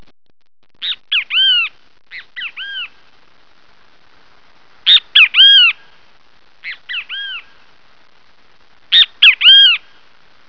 Olho-de-boi - Chant du "Bem-te-vi" - Borboleta-Coruja   cobra vert
bemtevi.wav